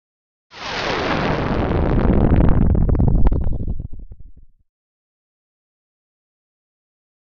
Disintegration Liquid Disintegration with LFE